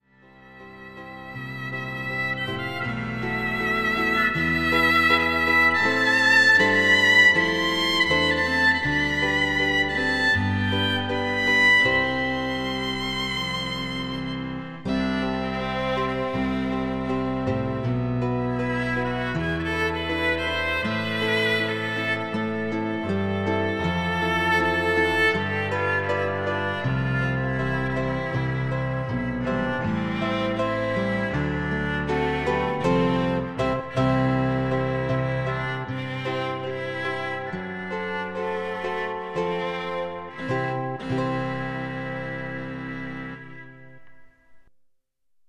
housle